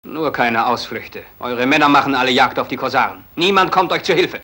Hörprobe des deutschen Synchronschauspielers
Vergeltung-Glemnitz03.mp3